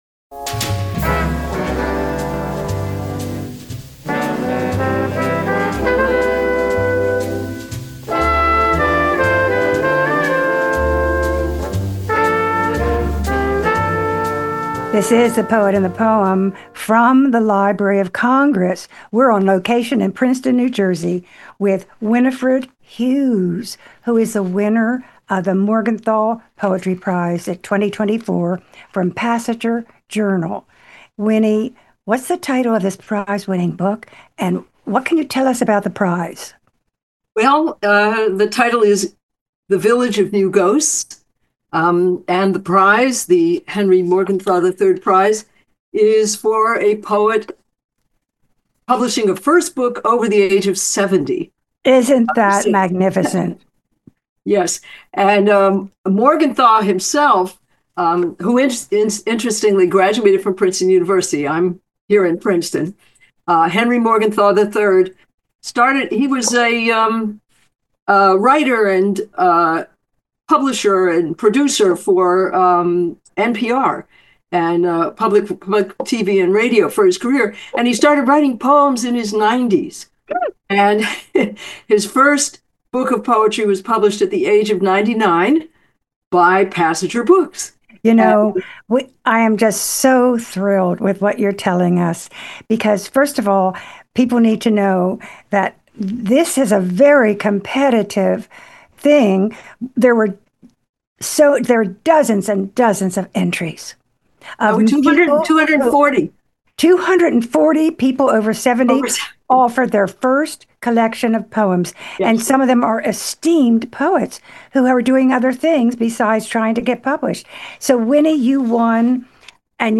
Interviews with U.S. Poets Laureate